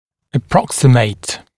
глагол [ə’prɔksɪmeɪt][э’проксимэйт]приближаться, сближаться, сблизить
approximate-гл.mp3